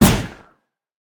Minecraft Version Minecraft Version latest Latest Release | Latest Snapshot latest / assets / minecraft / sounds / entity / wind_charge / wind_burst3.ogg Compare With Compare With Latest Release | Latest Snapshot
wind_burst3.ogg